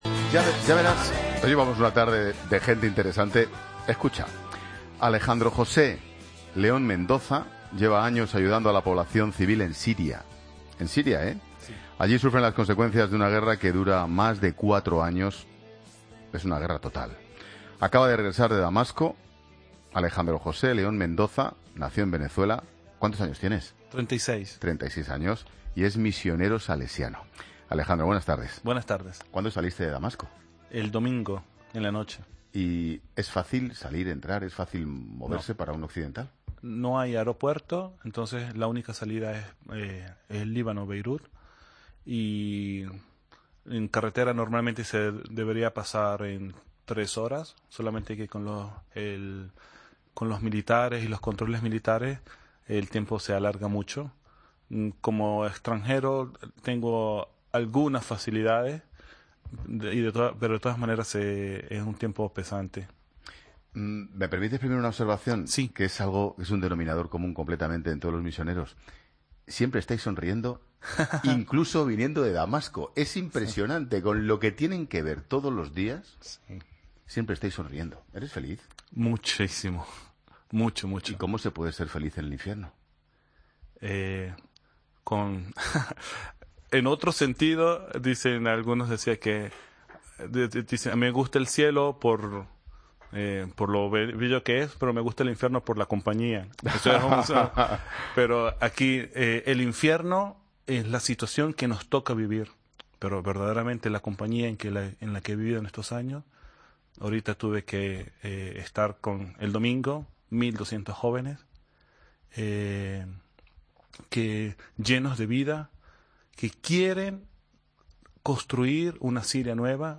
misionero salesiano, con Ángel Expósito